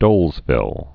(dŭlzvĭl)